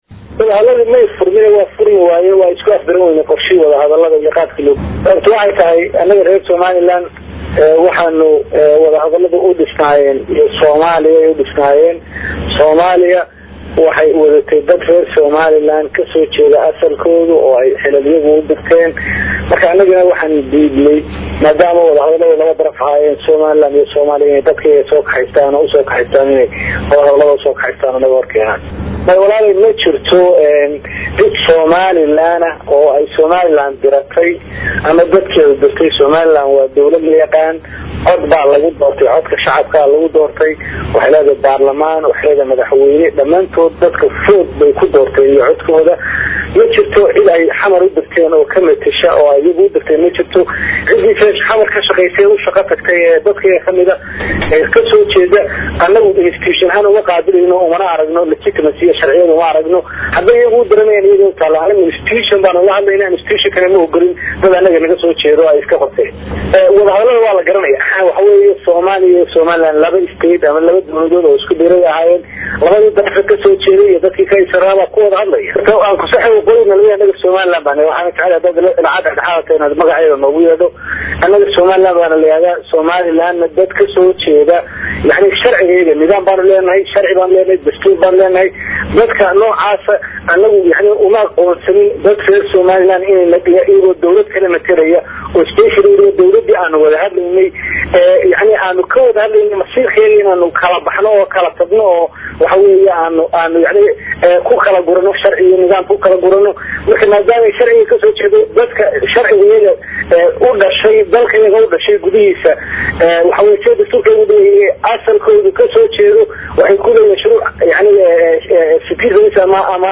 Dhageyso:Wasiirka Madaxtooyada Somaliland oo ka hadlay Ujeedada ay udiideen wada hadalada
Hargeysa(INO)-Wasiirka Madaxtooyada ee Somaliland Xirsi Cali Xaaji Xasan oo ka hadlayay magaalada ISTANBUL ayaa sheegay in ay is afgaran waayeen ayaga iyo wafdiga dawladda Soomaaliya usoo dirsatay wada xaajoodyada labada dhinac.